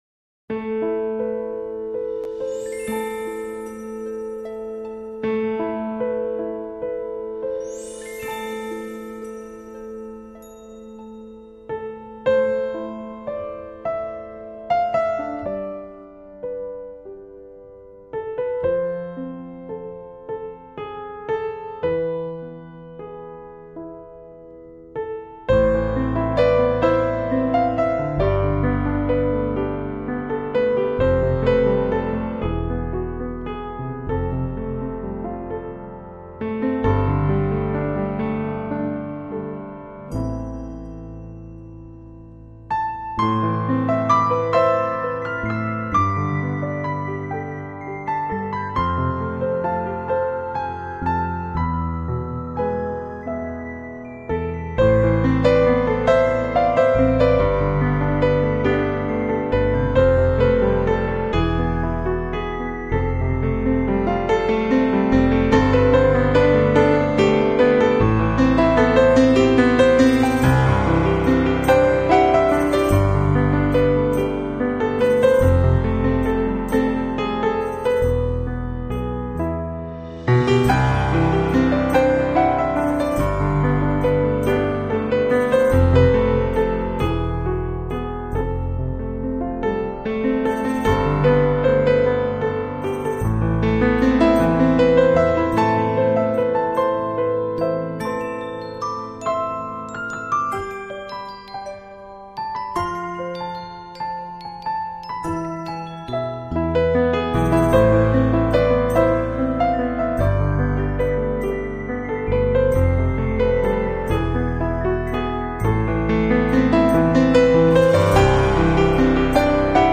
【纯色钢琴】